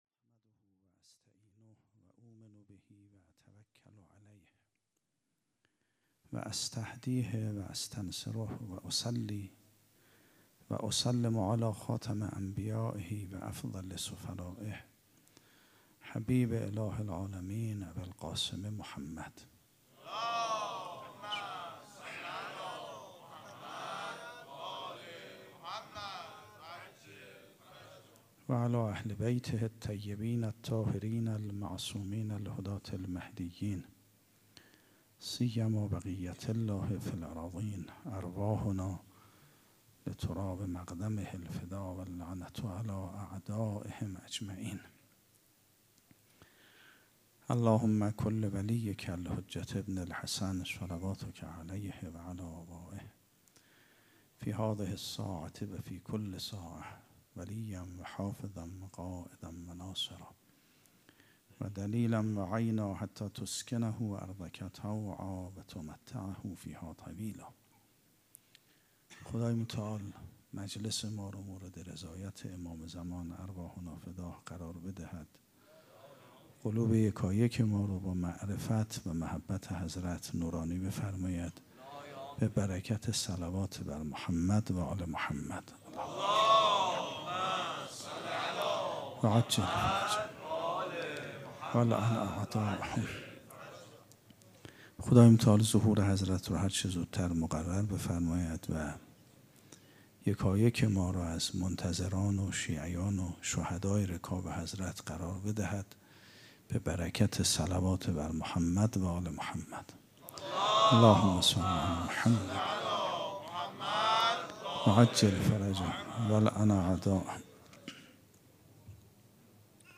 سخنرانی
سخنـــران آیت الله سید محمد مهدی میرباقری
sokhanrani.mp3